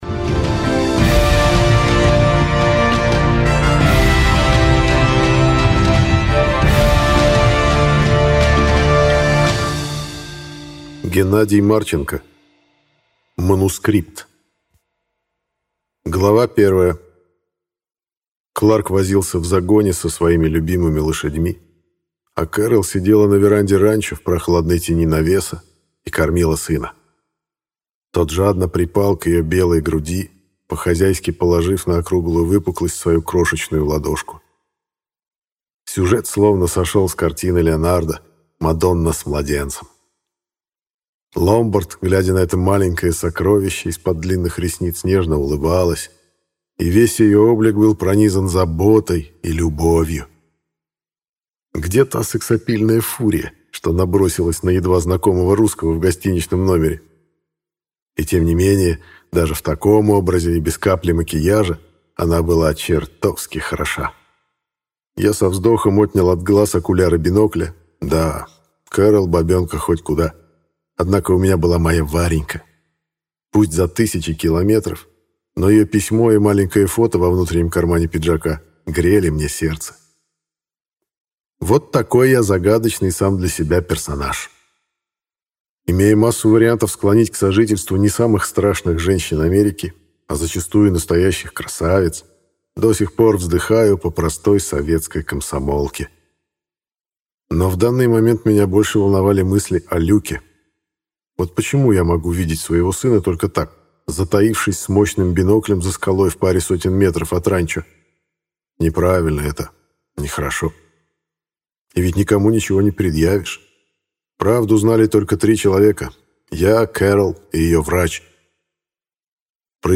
Аудиокнига Манускрипт | Библиотека аудиокниг